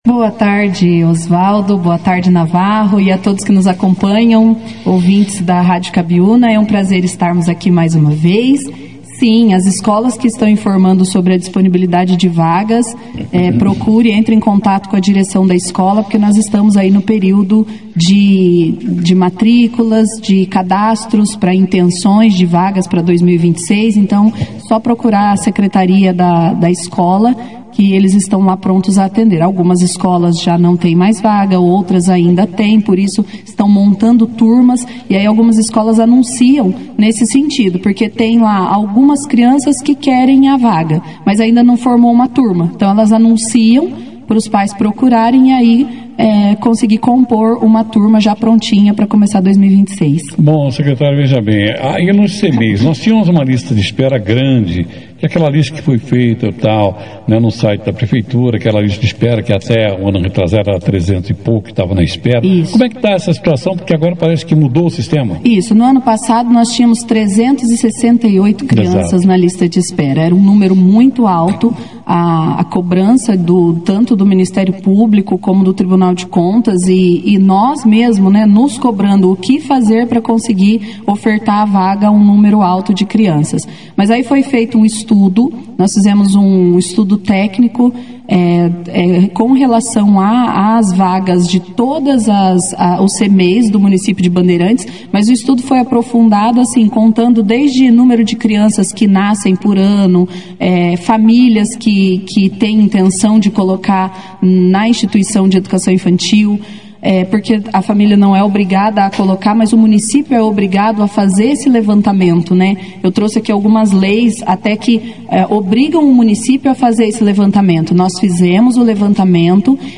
A Secretária Municipal de Educação de Bandeirantes, professora Aline Firmino das Neves Vasconcelos, participou da 2ª edição do Jornal Operação Cidade, nesta sexta-feira (28).